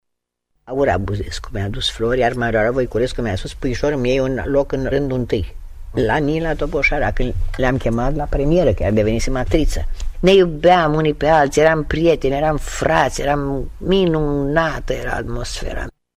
O ascultăm pe regretata actriță într-o înregistrare de acum 15 ani, pentru Radio România, amintind de profesorii și mentorii săi: